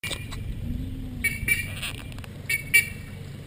Bandurria Austral (Theristicus melanopis)
Llamada.
Nombre en inglés: Black-faced Ibis
Fase de la vida: Adulto
Localización detallada: Río Chubut
Condición: Silvestre
Bandurria-austral--llamada.mp3